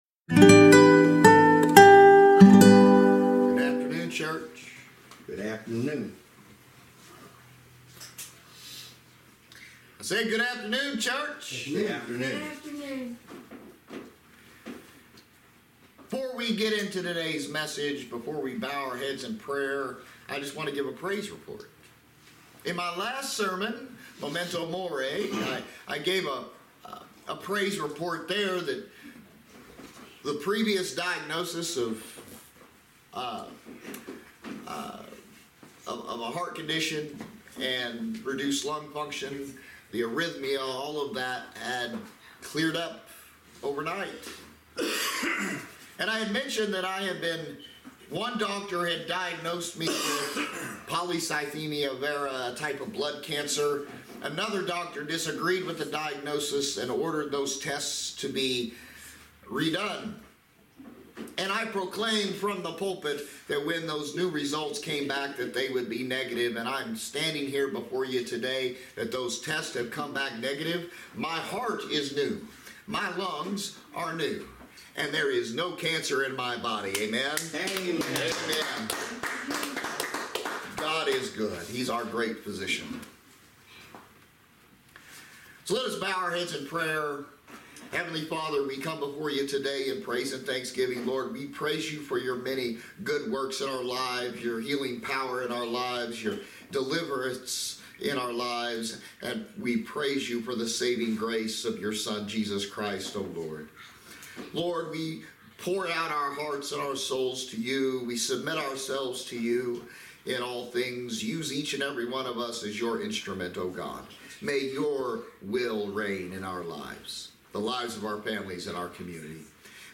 Passage: John 15:1-11, Service Type: Sunday Service